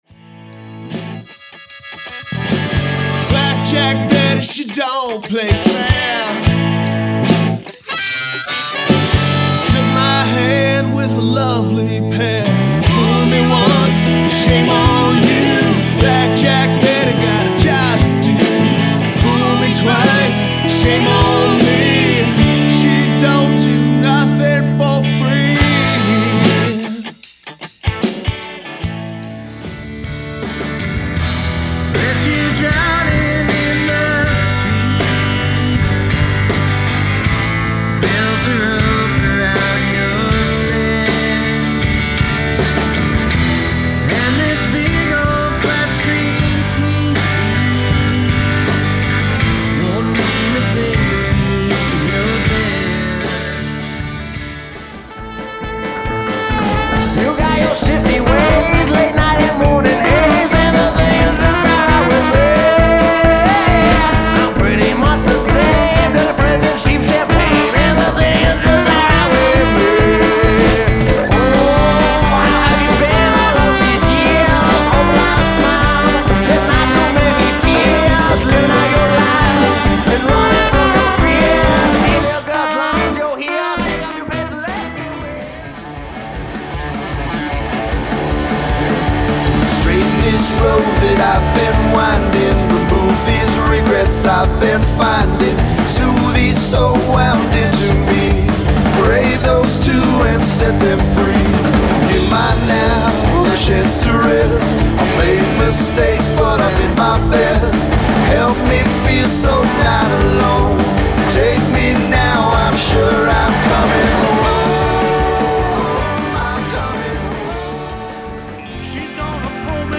Genre/Style:  Rock, alternative-country-rock
Genre/Style:  Rock, roots, alternative-country-rock
Genre/Syle:  rock, garage rock, roots rock, punk
Genre/Style: rock, roots, country-rock
guitar